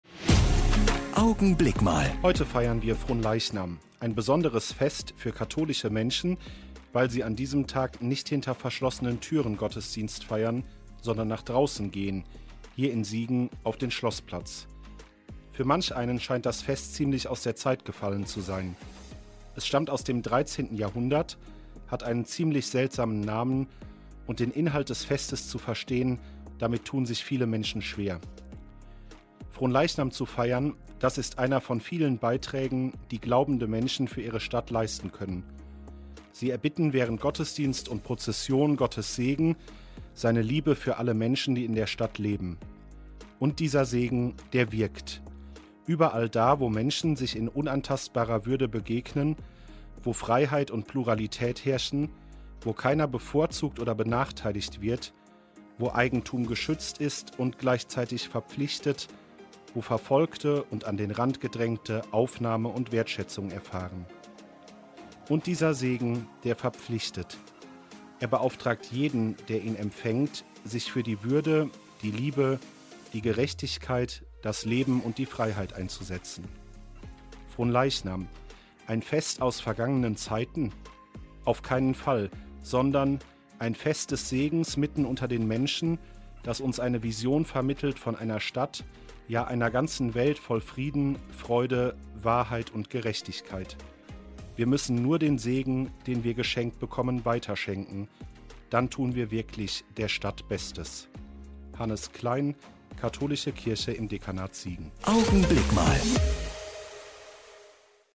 Feiertags gegen halb neun bei Radio Siegen zu hören: Gedanken von Leuten aus der Kirche - auch hier im Studioblog zum Nachhören.